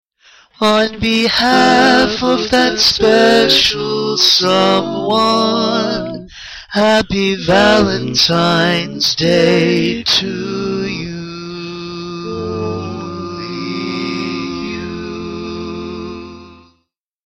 Key written in: F Major
Type: Barbershop